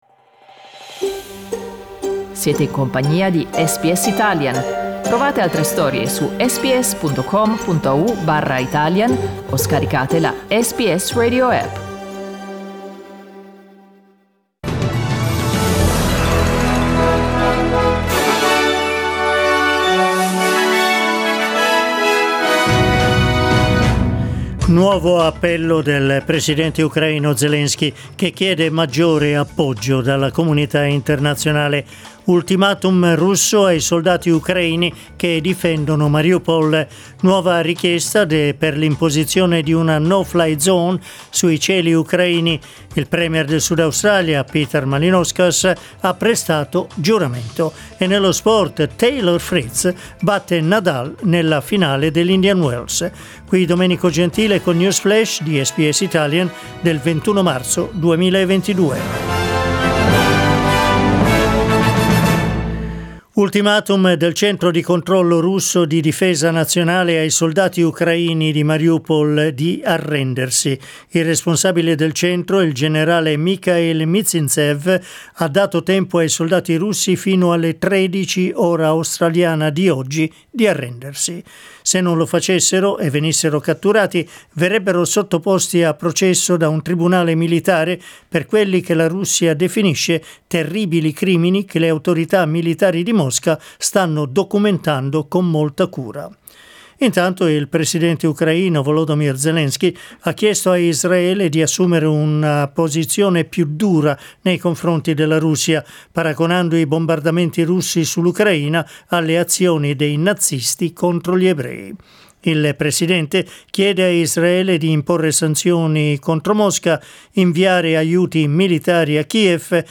News flash lunedì 21 mar2022
L'aggiornamento delle notizie di SBS Italian.